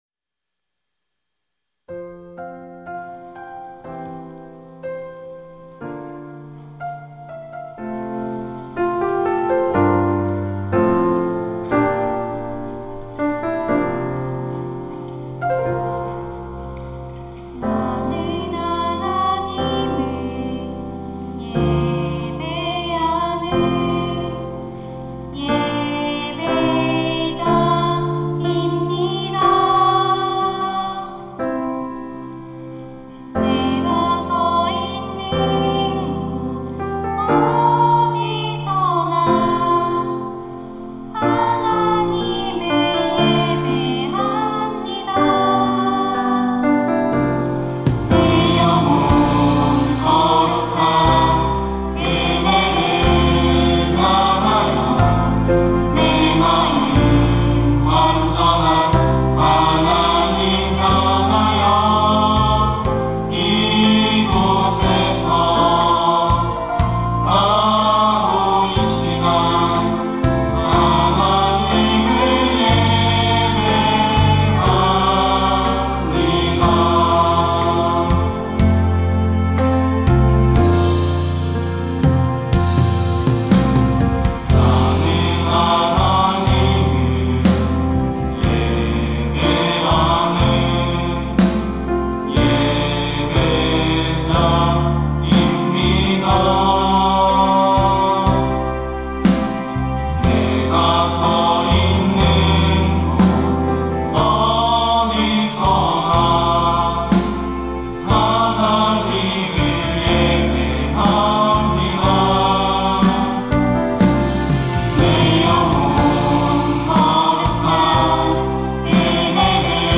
특송과 특주 - 나는 예배자입니다
청년부 임원, 교역자